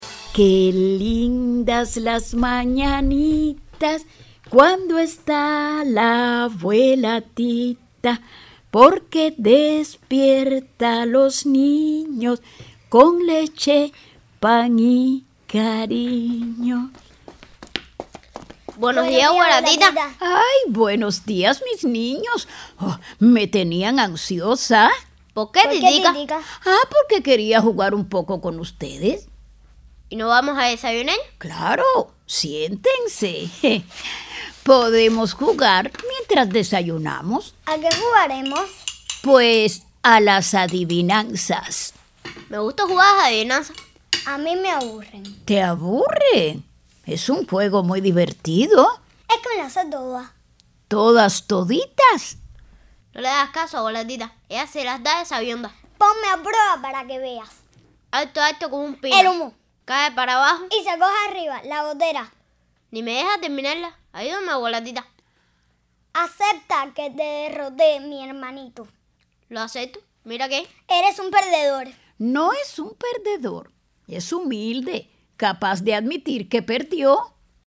INFANTIL.mp3